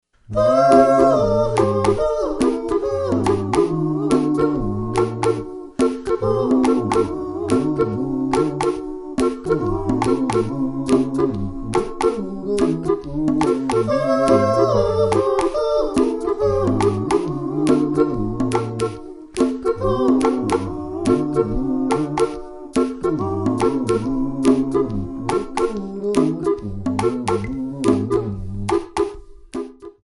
Backing track files: 1980s (763)
Buy With Backing Vocals.